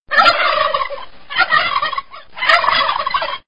جلوه های صوتی
دانلود صدای بوقلمون از ساعد نیوز با لینک مستقیم و کیفیت بالا